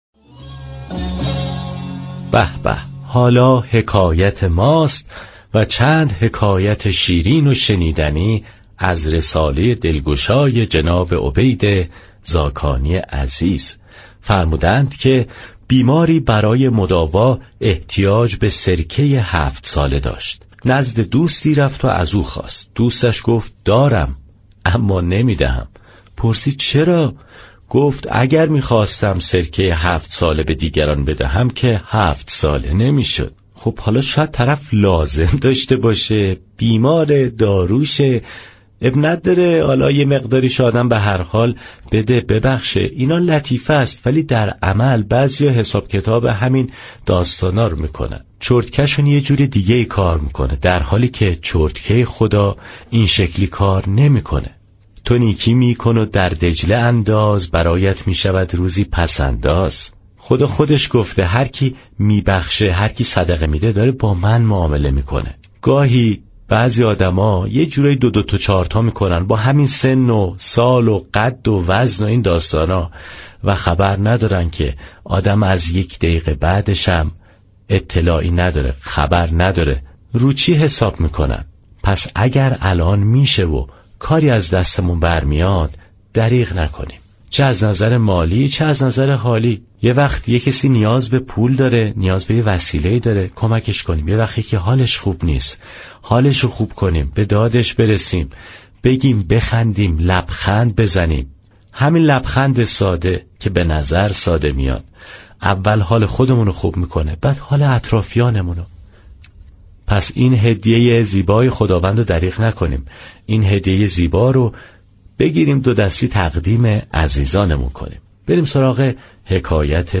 حكایت طنز